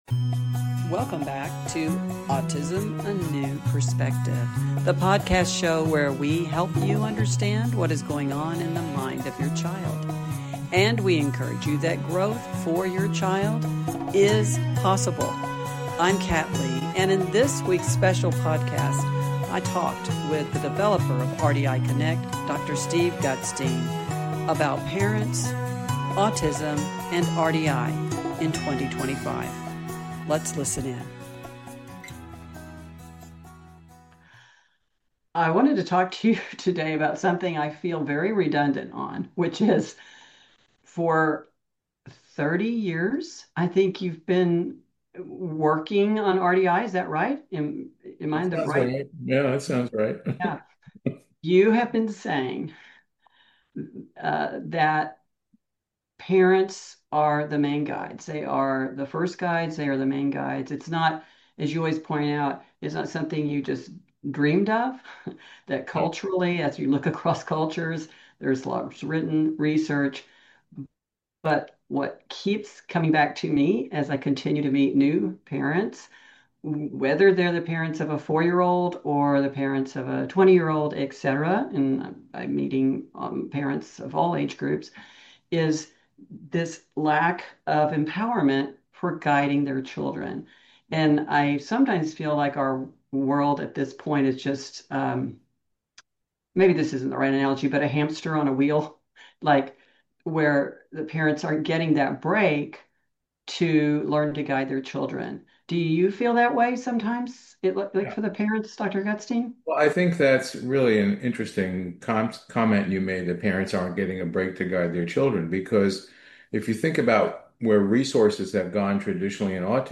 RDI: An Interview With a Former Student